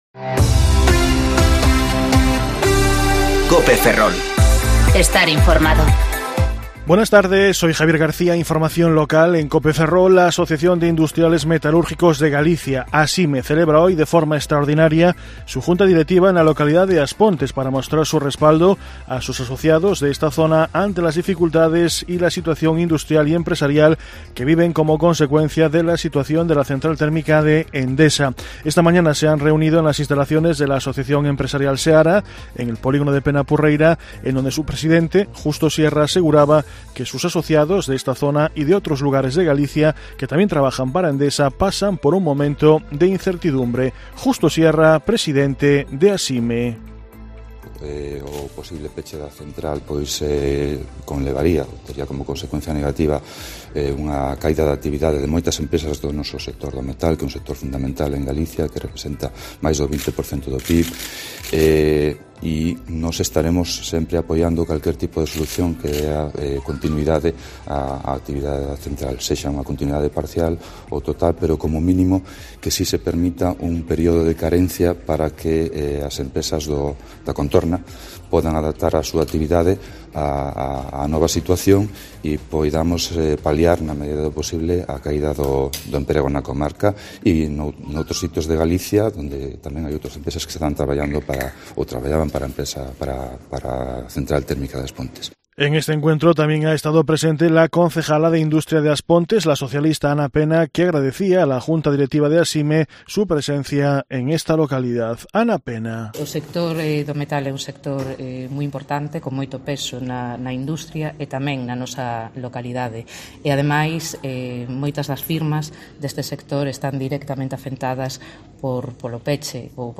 Informativo Mediodía COPE Ferrol 30/1/2020 (De 14,20 a 14,30 horas)